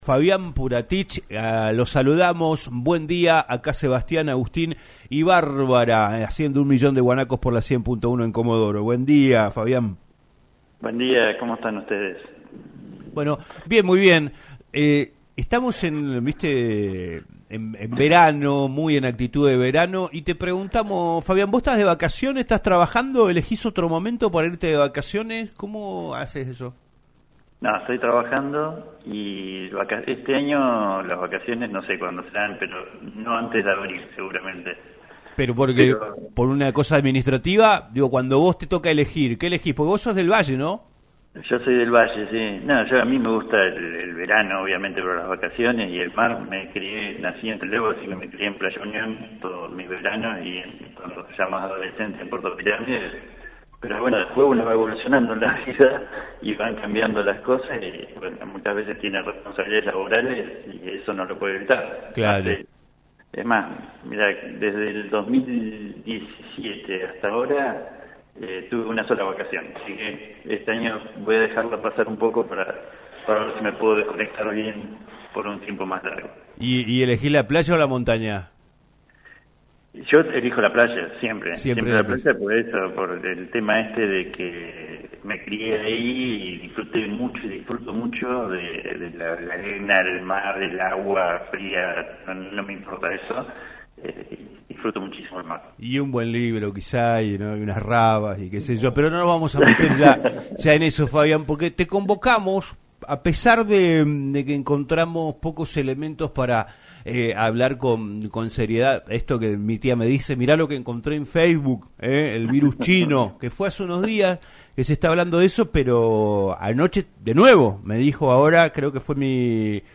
Fabián Puratich, exministro de Salud de la provincia de Chubut, habló en "Un Millón de Guanacos" por LaCienPuntoUno sobre el aumento de las infecciones respiratorias agudas, incluidas la gripe estacional, el virus respiratorio sincitial (VRS) y el metapneumovirus humano (HMPV) en China, que generó la fake news de que el gigante asiático se había declarado en “estado de emergencia” ya que ni las autoridades sanitarias chinas ni la Organización Mundial de la Salud (OMS) emitieron al día de la fecha un alerta sanitaria o emergencia de salud pública en China en relación con el HMPV.